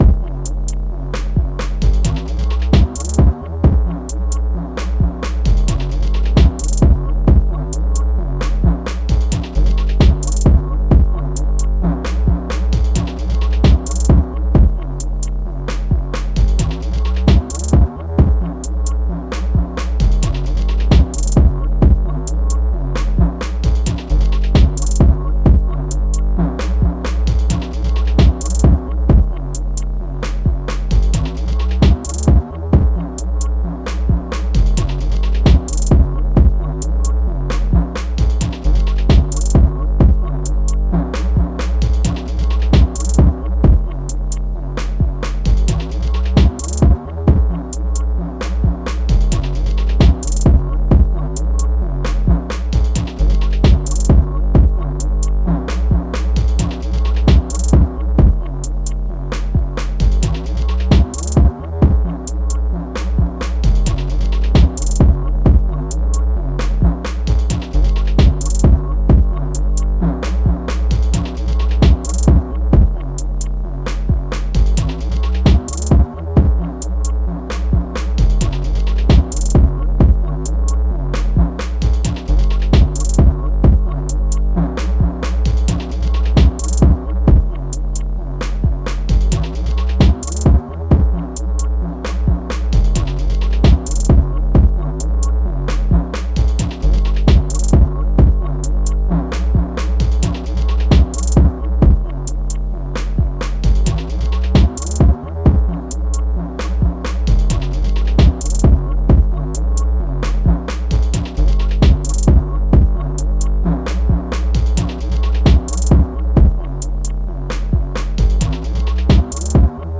Gm - 132.wav
A well constructed and tuned phonk sample
Loudest frequency 753 Hz